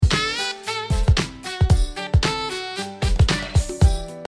点点点-连续.mp3